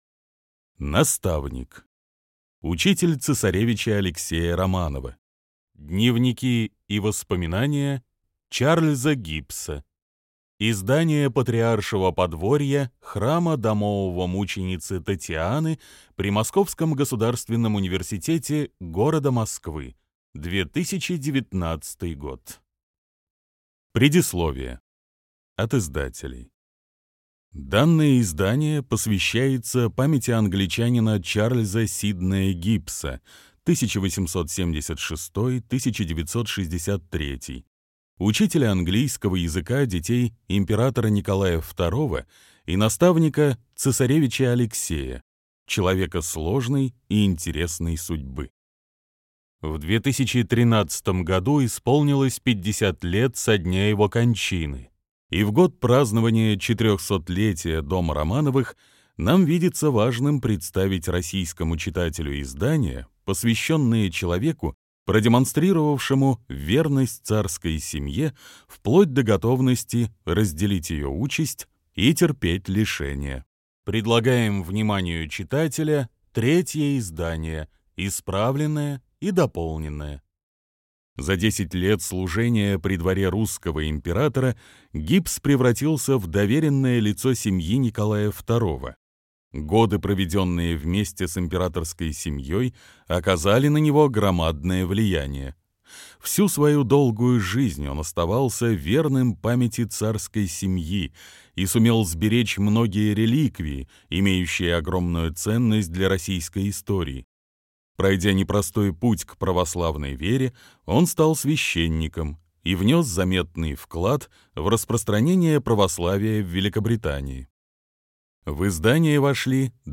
Аудиокнига Наставник. Учитель Цесаревича Алексея Романова. Дневники и воспоминания Чарльза Гиббса | Библиотека аудиокниг